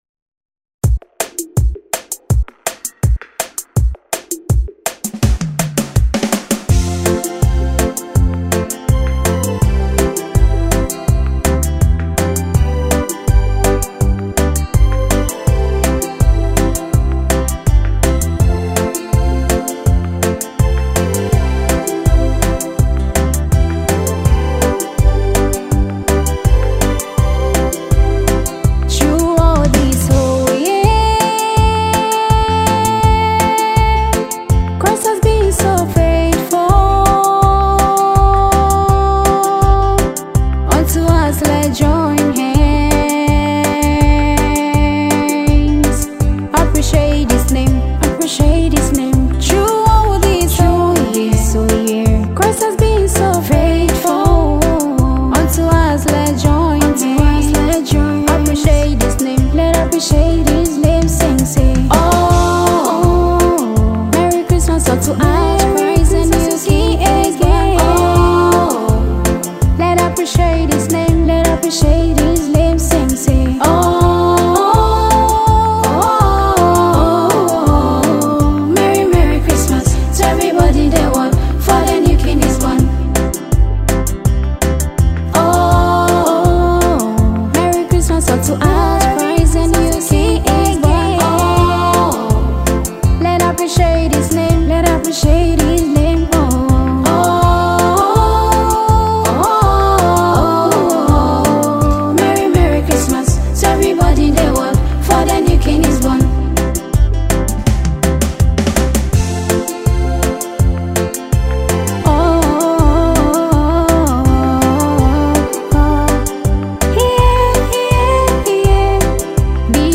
A new appreciative song comes from a dubbed artist